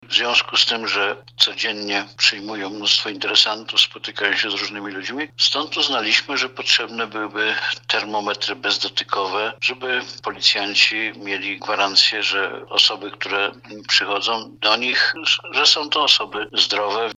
Mówi starosta Jerzy Sudoł.